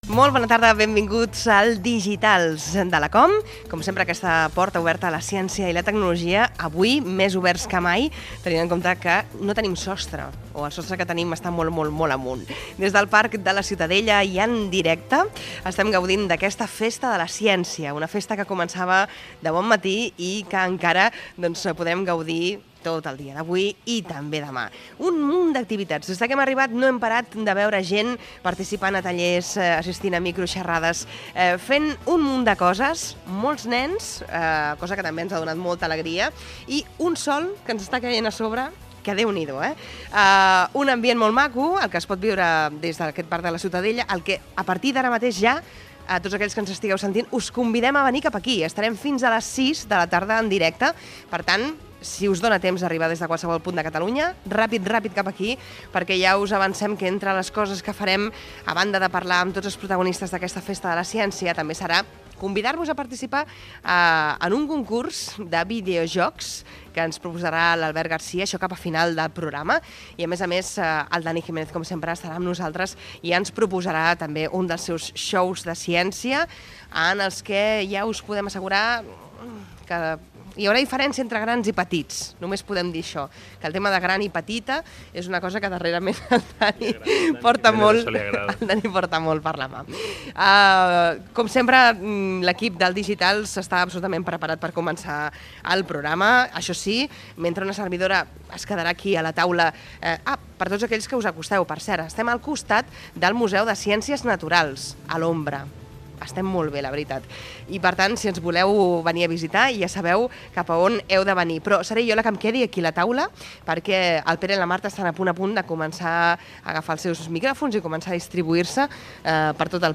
Presentació del programa especial, fet des de la Festa de la Ciència al Parc de la Ciutadella de Barcelona
Divulgació